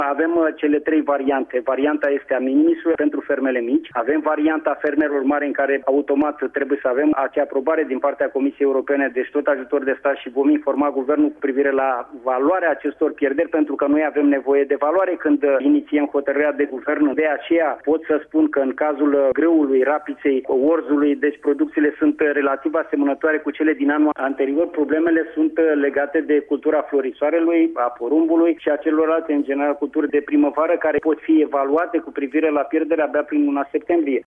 Ei aşteaptă ajutor de la Ministerul Agriculturii, dar secretarul de stat Daniel Botănoiu a explicat, astăzi, la Radio România, că orice schemă de sprijin este iniţiată numai în urma unor pierderi de culturi de cel puţin 30%.